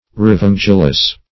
Revengeless \Re*venge"less\